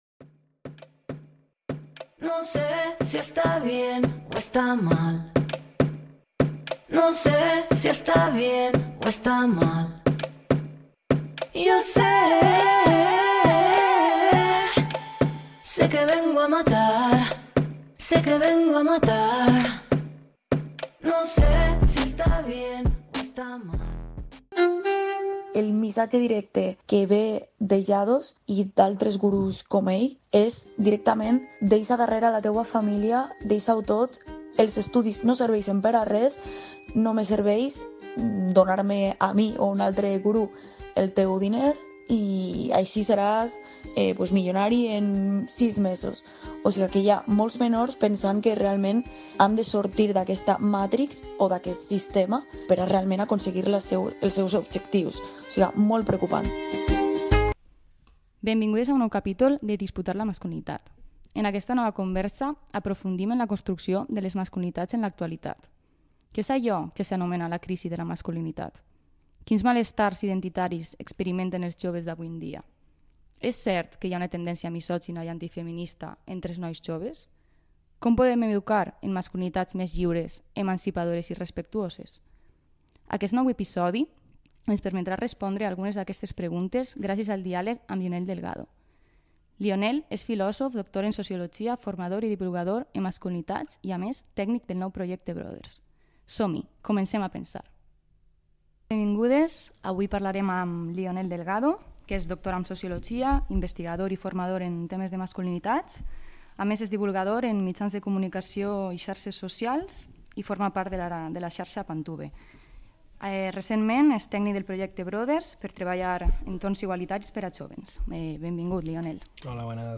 Capítol 3 Pòdcast Masculinitats en disputa: Elements per transformar les masculinitats. En aquesta nova conversa aprofundim en la construcció de les masculinitats en l’actualitat.